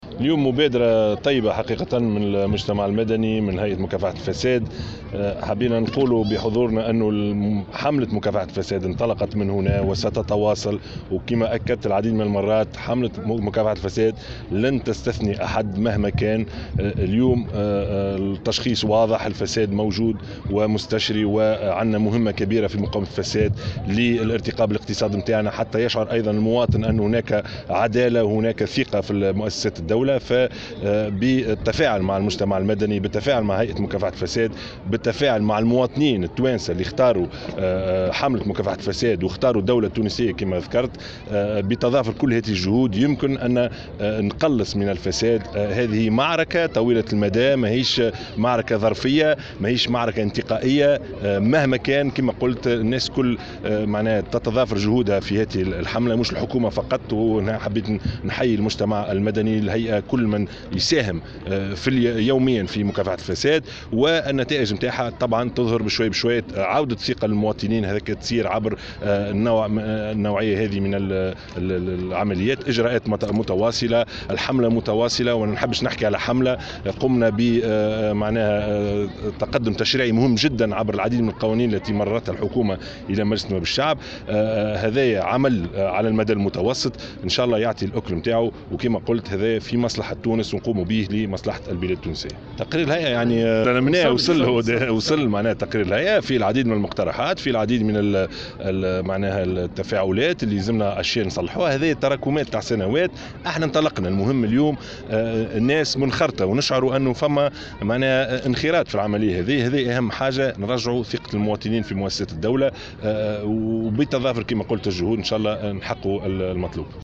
La campagne anti-corruption se poursuivra et n'exclura personne, a déclaré dimanche le chef du gouvernement Youssef Chahed.